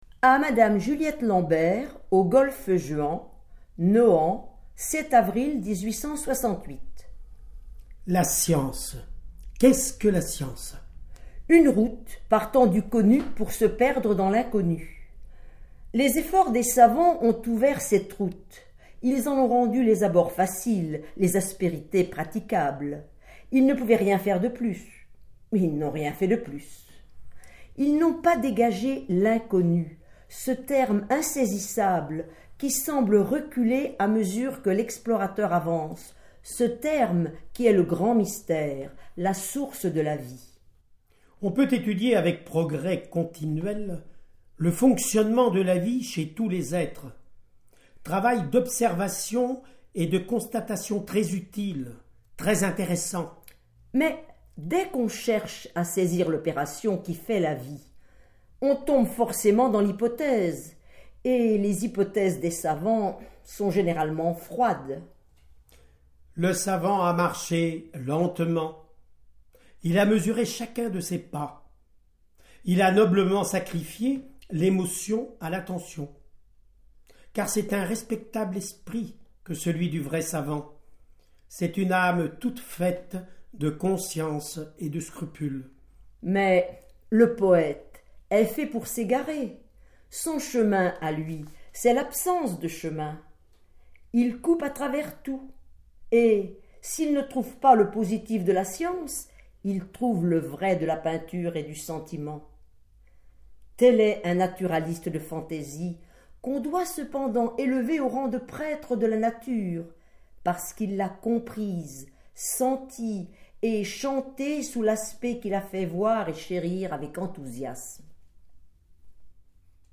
• Lectures des textes de George Sand sur les Sciences, réalisées à l’occasion du colloque George Sand et les sciences et vie de la terre et de l’exposition au Muséum d’histoire naturelle de Bourges